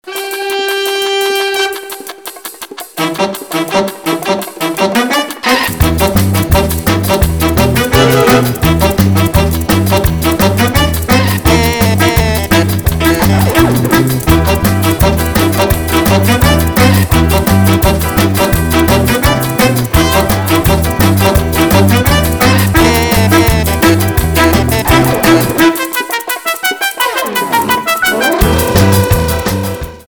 танцевальные
без слов , инструментальные , труба
позитивные
веселые , барабаны